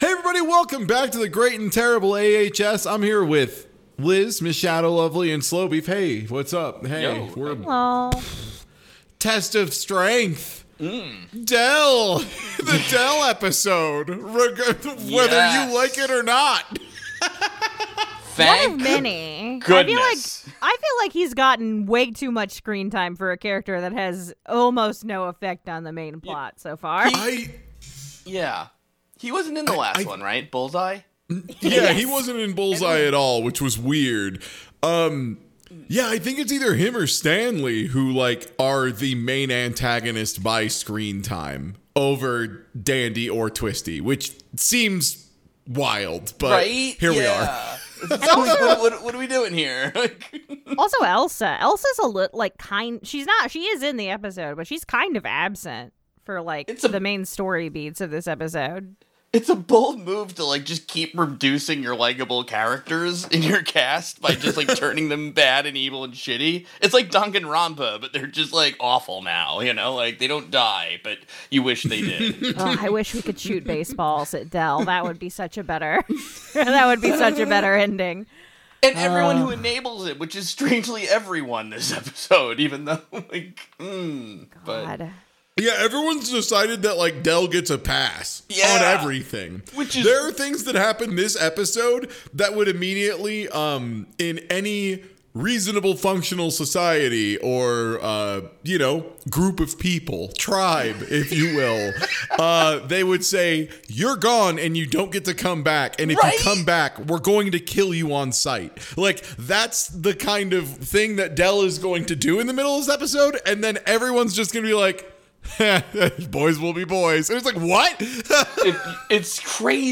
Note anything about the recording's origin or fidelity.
whew, this lady is on one (apologies for a audio differences, we had a small recording issues so I had to be a lil' more aggressive with the compression)